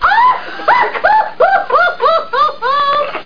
LAUGH.mp3